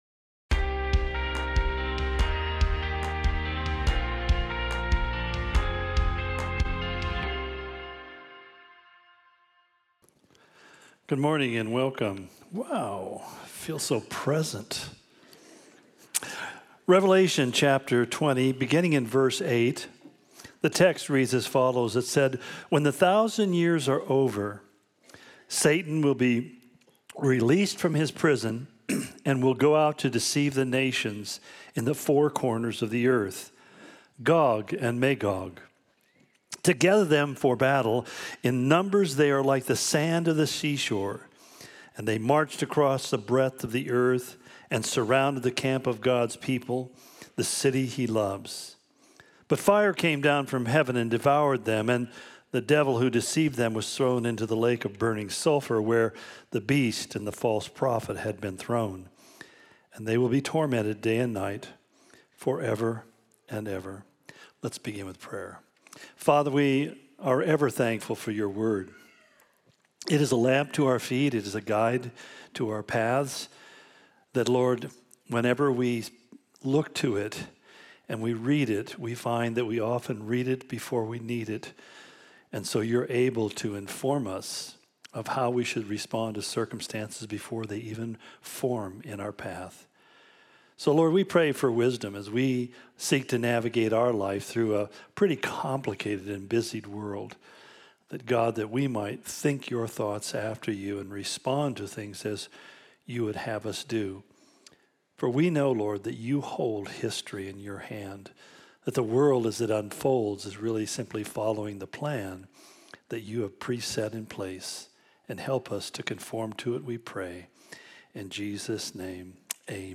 Similar to Calvary Spokane Sermon of the Week